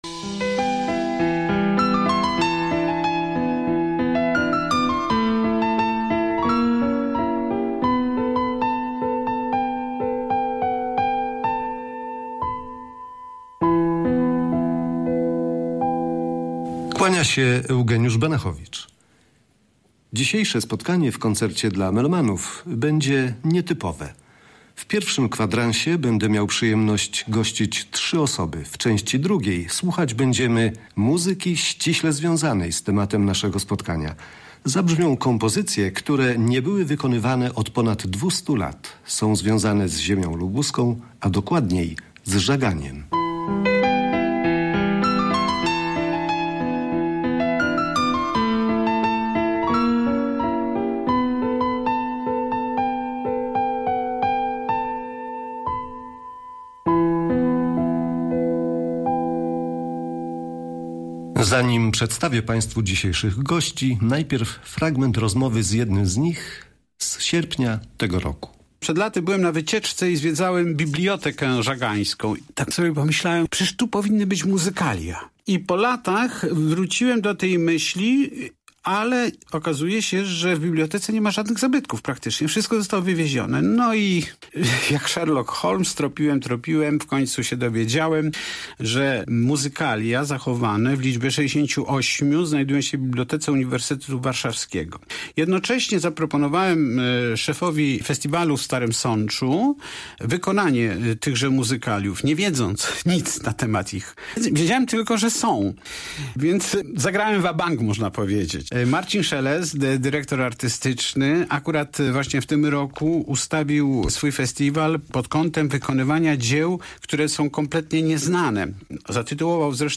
Listopadowy niedzielny wieczór na falach Radia Zachód rozbrzmiewał muzyką z Żagania.
Zapraszamy do wysłuchania rozmowy oraz pięknej muzyki stworzonej przed wiekami w naszym mieście i wykonanych przez kwintet smyczkowy.